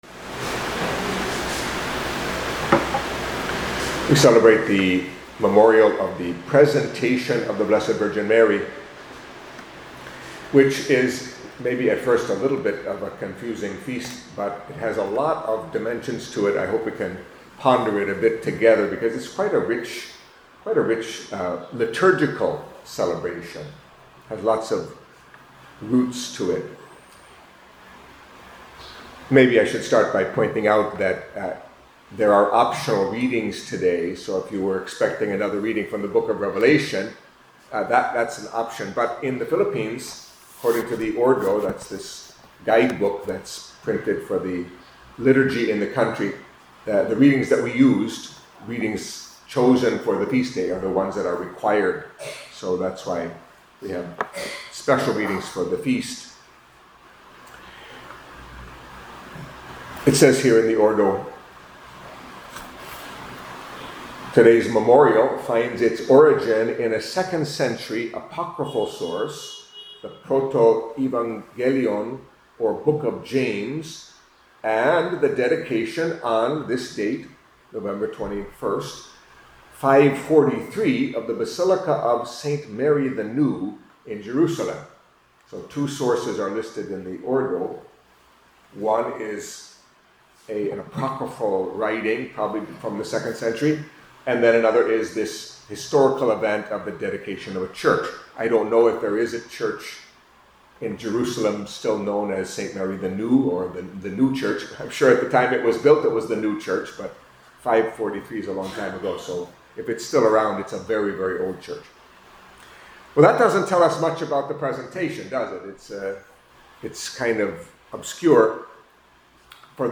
Catholic Mass homily for Thursday of the Thirty-Third Week in Ordinary Time